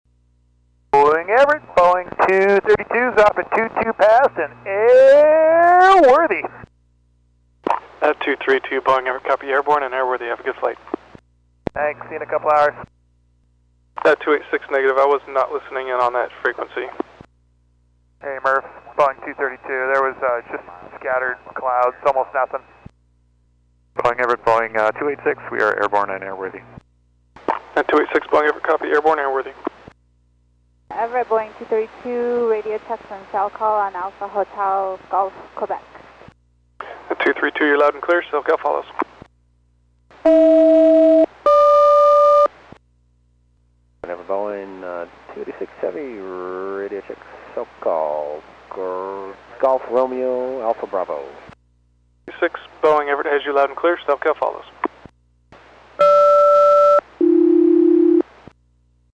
Turkish Airlines 787-9 TC-LLN back from a B1 flight. Departure audio